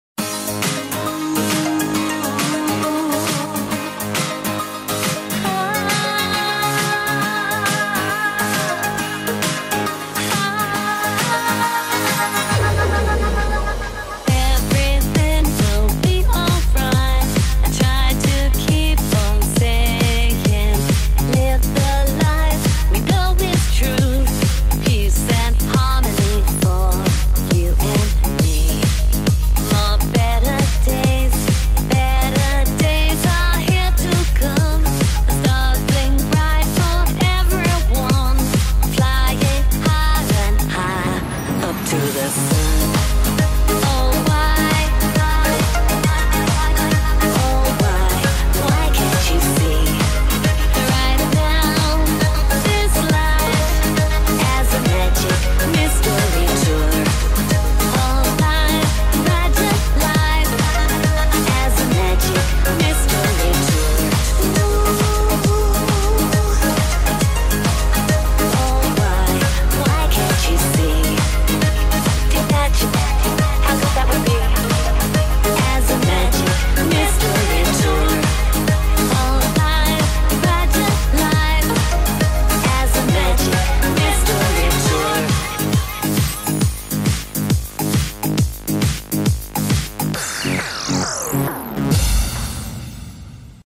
BPM136
Audio QualityPerfect (High Quality)
A fun swing type song that'll make you fall in love!